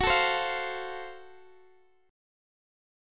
F#mM7b5 chord